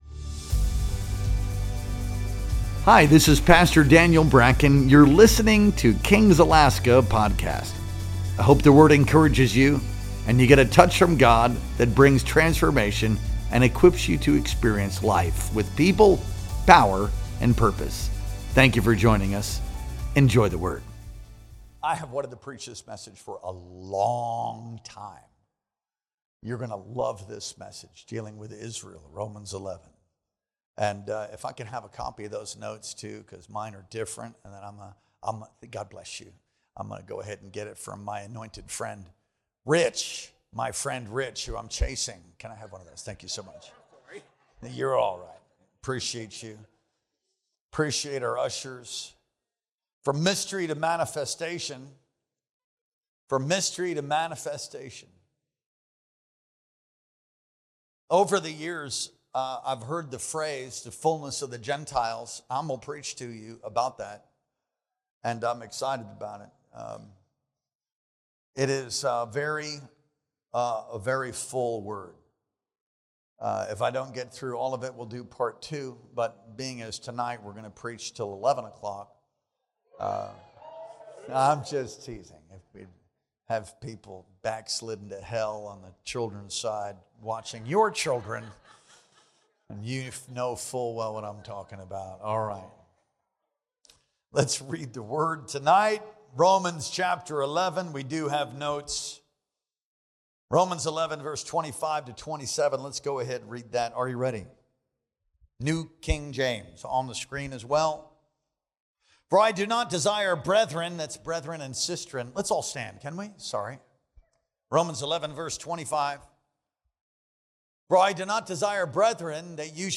Our Wednesday Night Worship Experience streamed live on April 2nd, 2025.